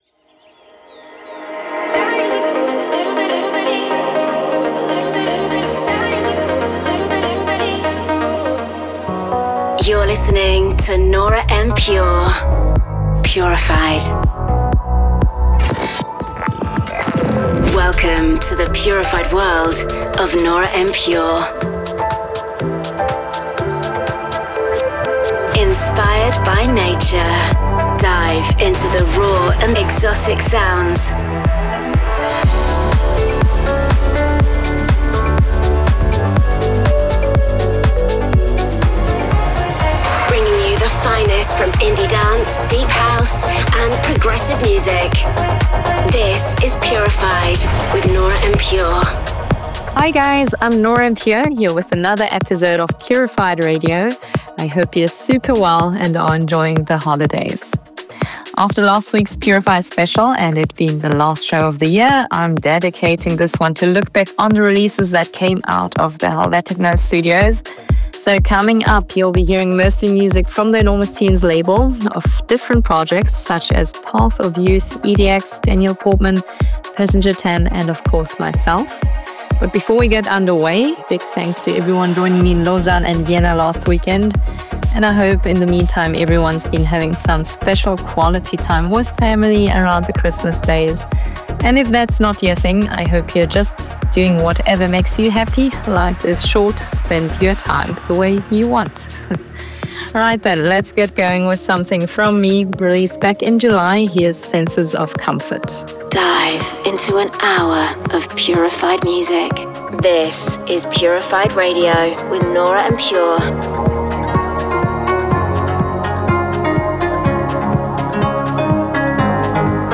music DJ Mix in MP3 format
Genre: Progressive house